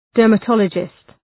Shkrimi fonetik{,dɜ:rmə’tɒlədʒıst}
dermatologist.mp3